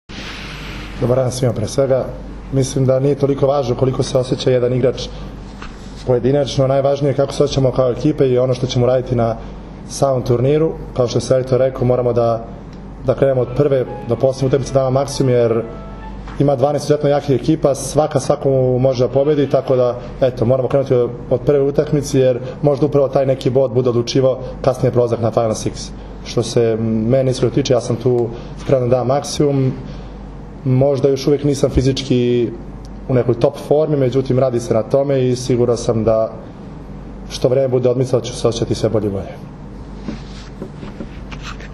Tim povodom, danas je u beogradskom hotelu “M” održana konferencija za novinare, na kojoj su se predstavnicima medija obratili Nikola Grbić, Dragan Stanković, Aleksandar Atanasijević i Miloš Nikić.
IZJAVA ALEKSANDRA ATANASIJEVIĆA